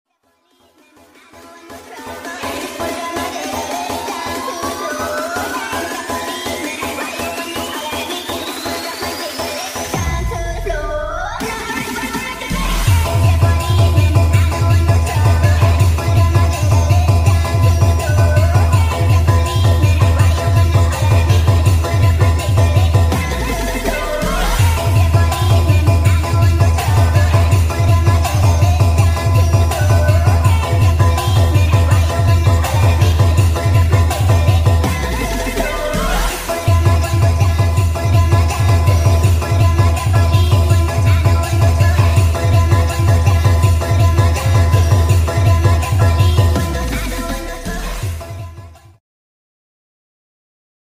speed up + reveb